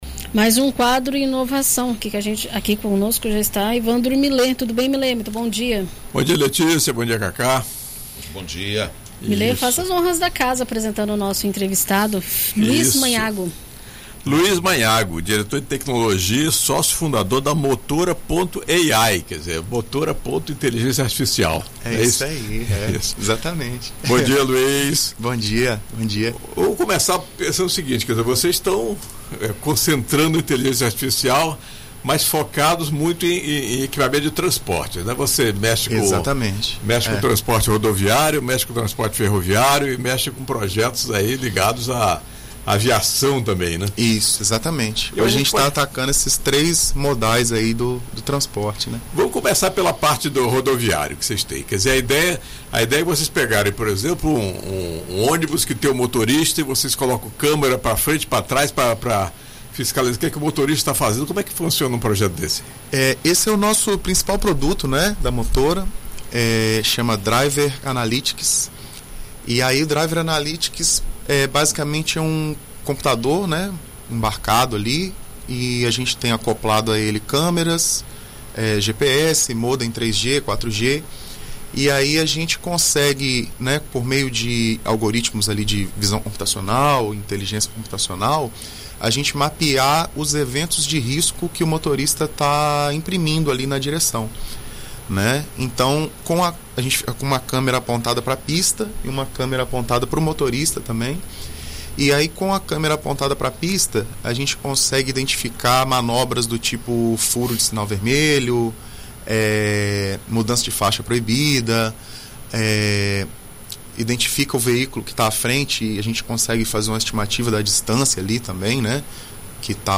recebe no estúdio